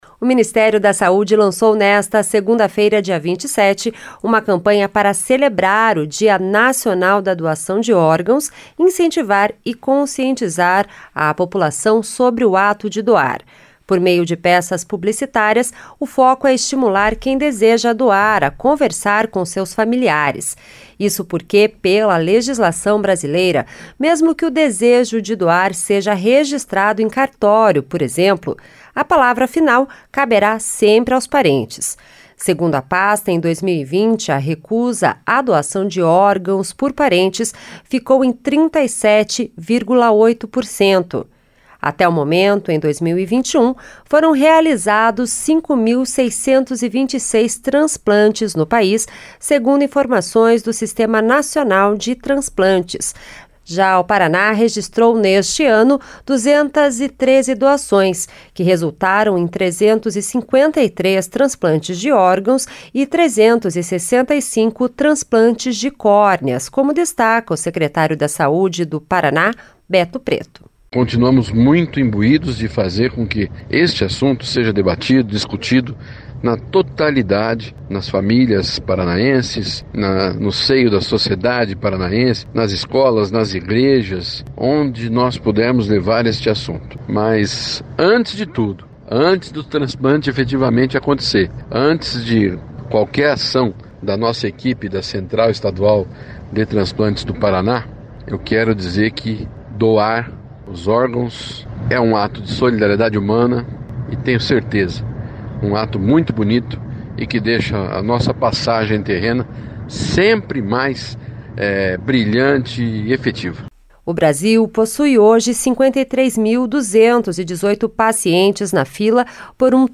SONORA BETO PRETO